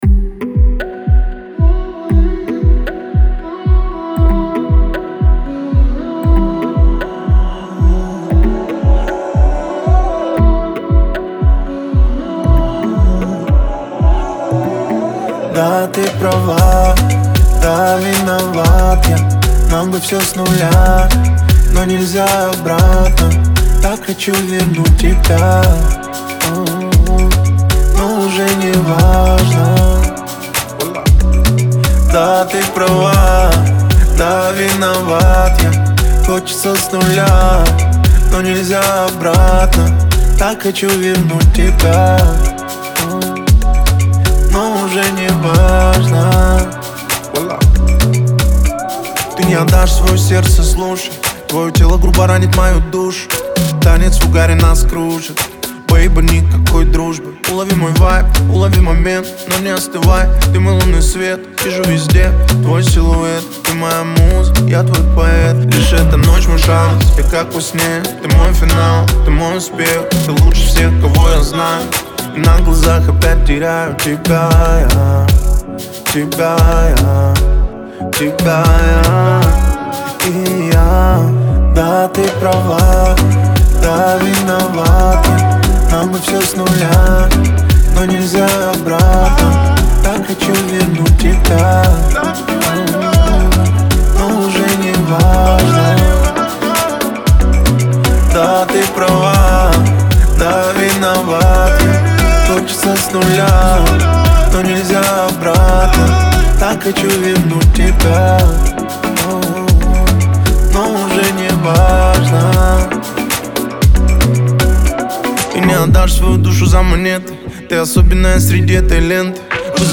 Звучание песни отличается мелодичностью и искренностью
наполнен глубокими эмоциями